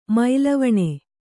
♪ mailavaṇe